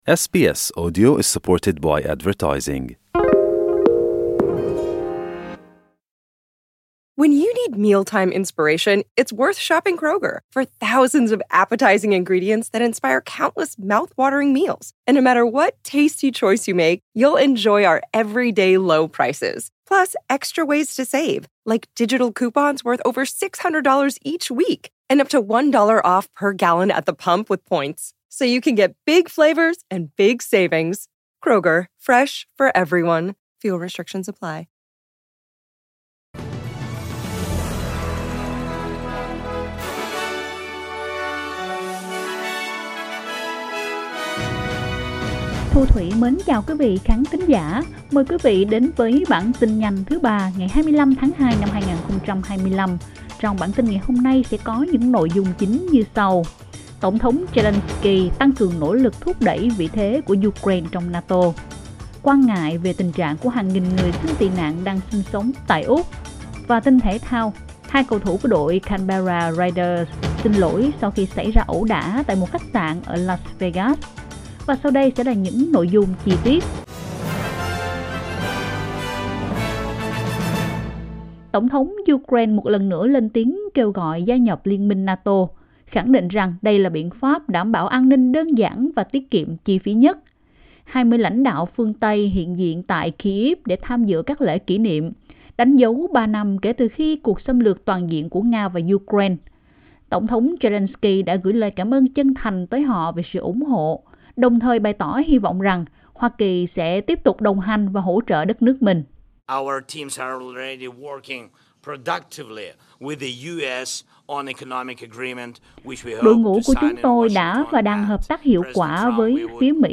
Vietnamese news bulletin